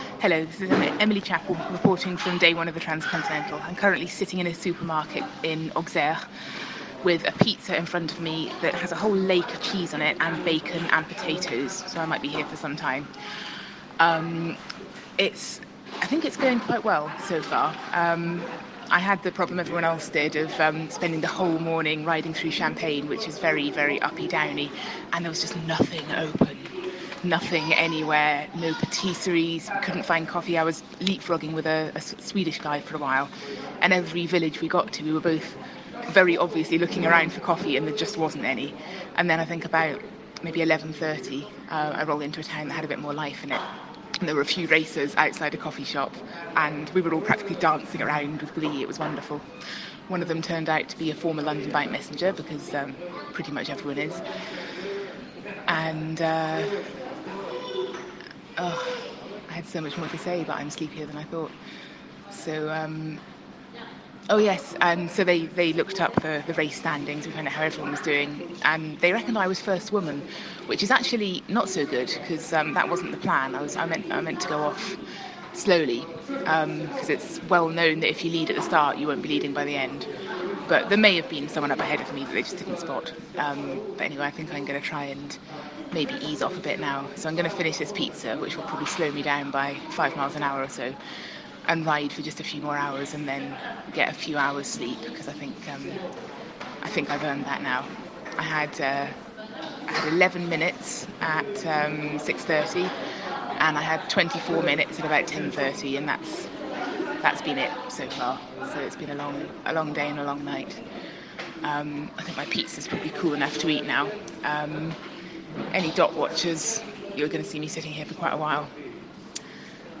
On the road report